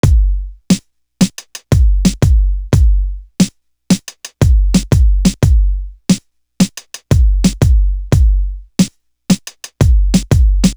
D Elite Drum.wav